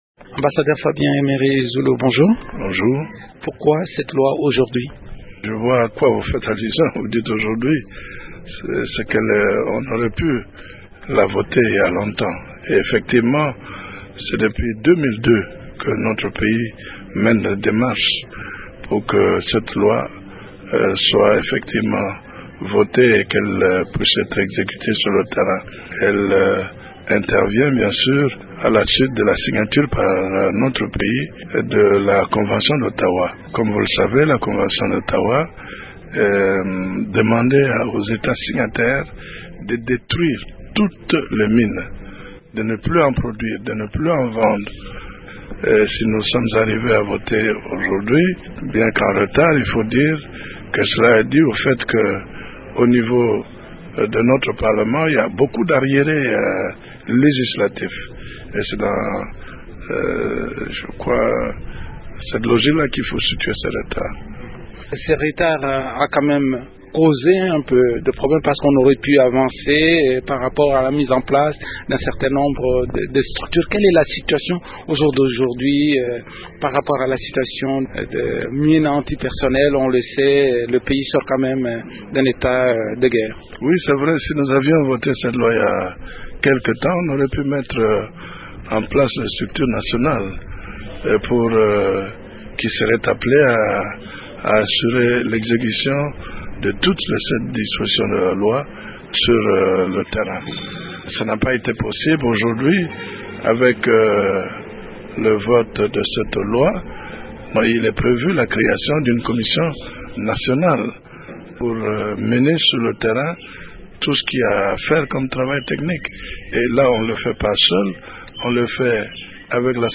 Le député Fabien Emery Zulu Kilo-Abi, coauteur de cette loi, en explique les grandes lignes.